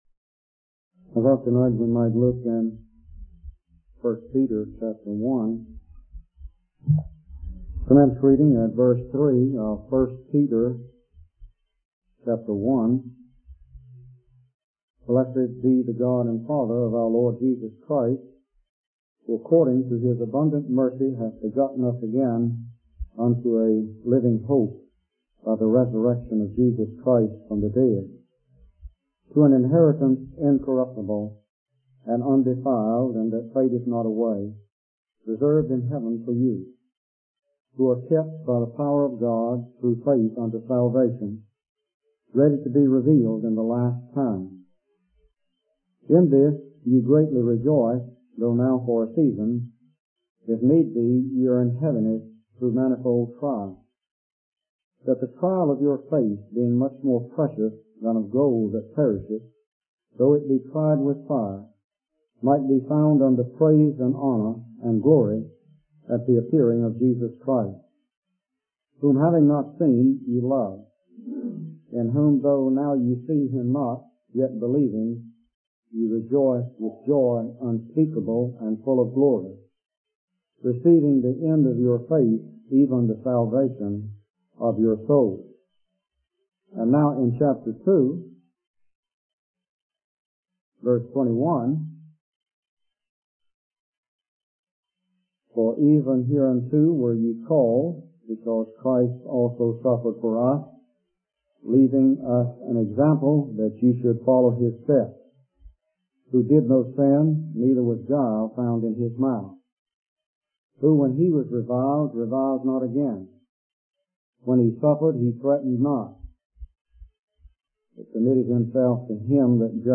In this sermon, the preacher emphasizes the importance of living a life committed to God, even in the face of trials and conflicts with the world. He encourages believers to trust in God's strength and power, rather than relying on their own abilities or influence. The preacher highlights the example of Jesus Christ, who suffered without sinning, as the perfect model for enduring suffering.